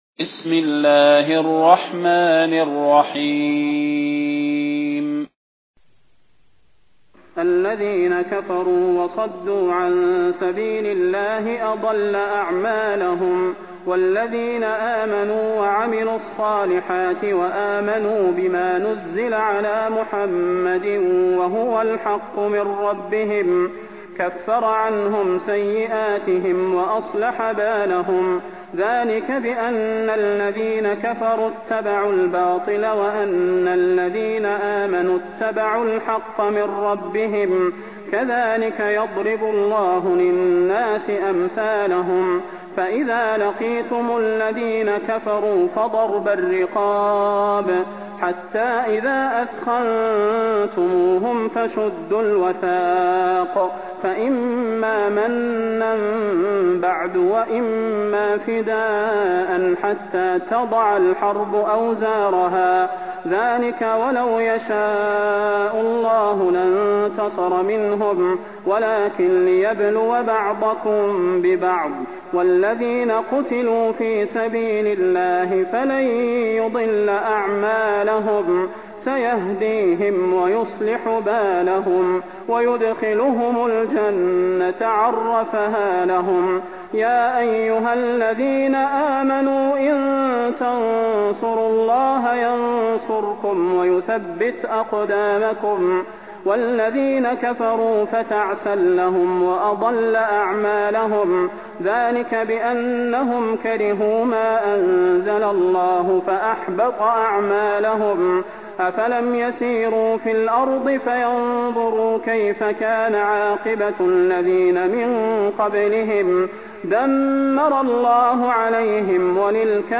المكان: المسجد النبوي الشيخ: فضيلة الشيخ د. صلاح بن محمد البدير فضيلة الشيخ د. صلاح بن محمد البدير محمد